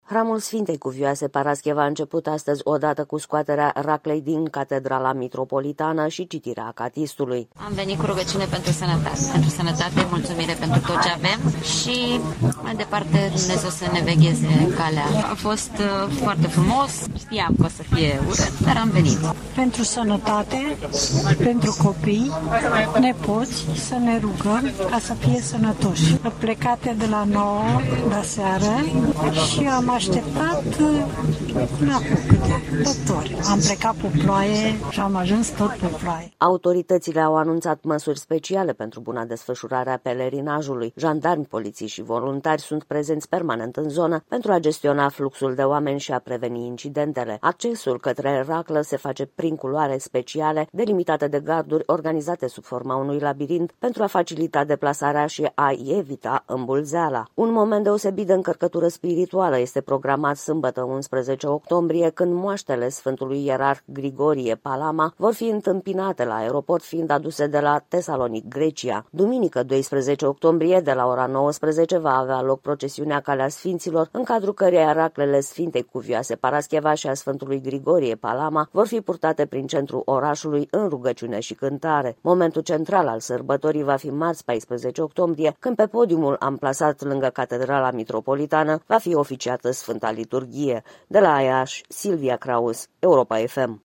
„Am venit cu rugăciune pentru sănătate, mulțumire, pentru tot ce avem și, mai departe, Dumnezeu să ne vegheze calea. A fost foarte frumos. Știam că timpul o să fie urât, dar am venit”, a declarat o credincioasă.
„Pentru sănătate, pentru copii, nepoți, să ne rugăm ca să fie sănătoși. Sunt plecată de la 21:00 de aseară și am așteptat opt ore. Am plecat pe ploaie și am ajuns tot pe ploaie”, a spus o doamnă.